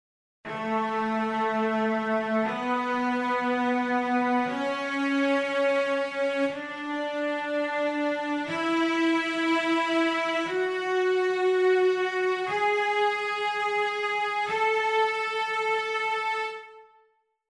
Major Scale:
Click to hear the A Major scale.
a_major_scale.mp3